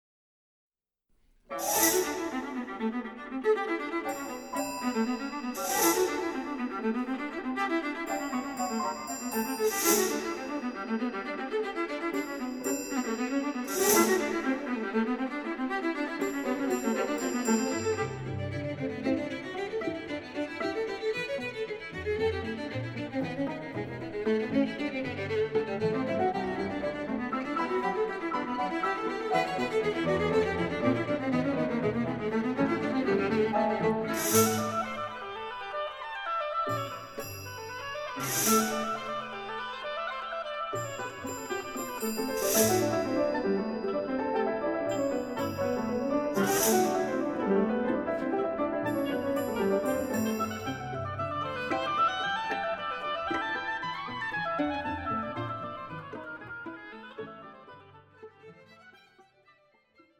Oboe
Violin
Percussion